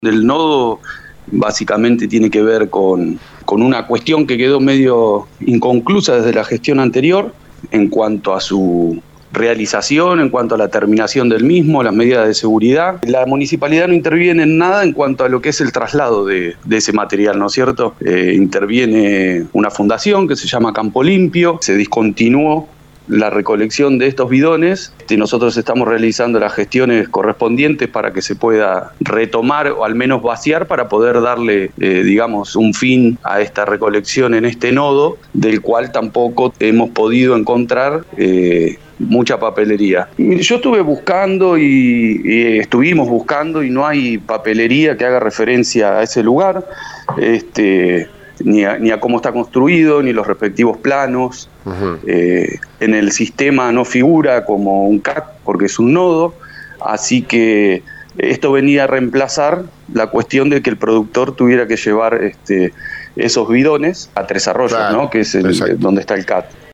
En diálogo con LU24, el delegado municipal de Orense, Julián Pérez Olivero, arrojó luz sobre la alarmante situación del nodo de acopio de agroquímicos de la localidad.